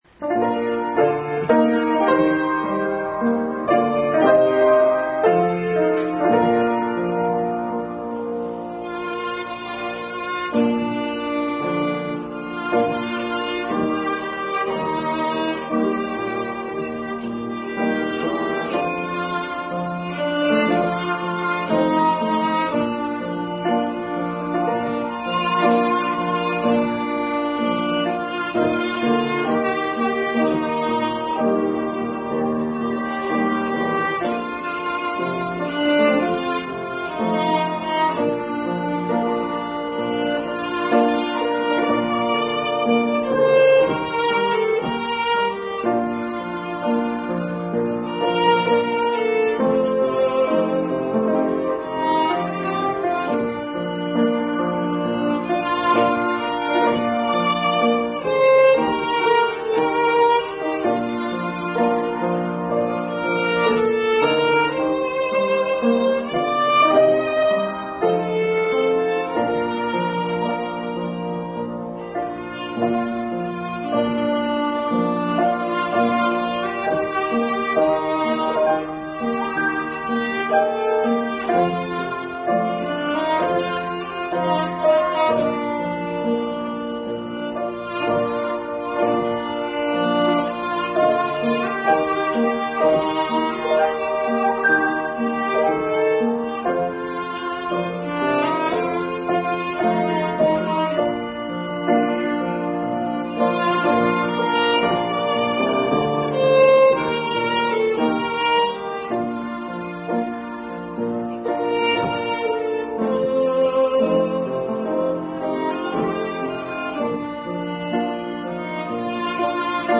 | Special Music in the Family Bible Hour 3/10/2019